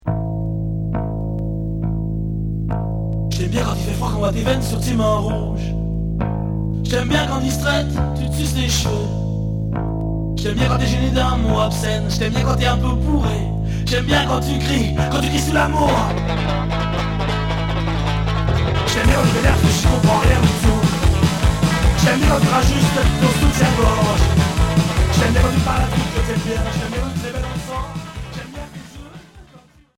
Alternatif